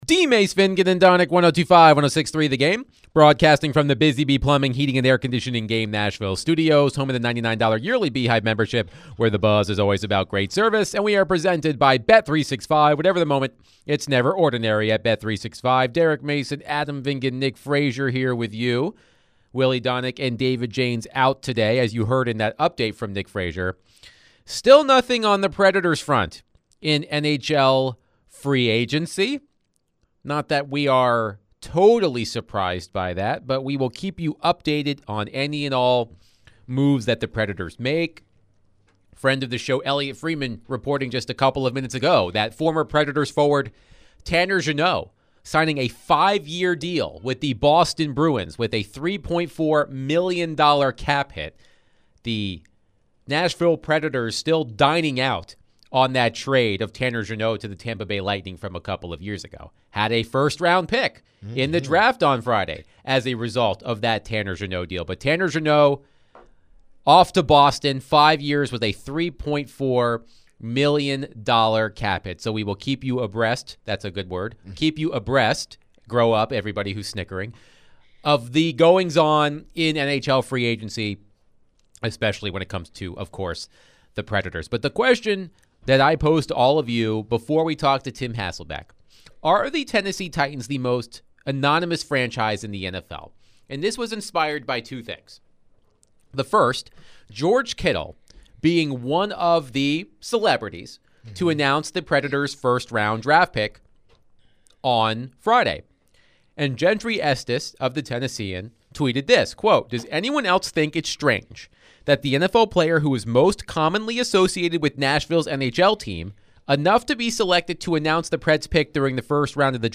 In the second hour, the guys continue the topic of who is the most anonymous NFL franchise. They later answered some texts and calls.